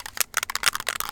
spraycan_rattle.ogg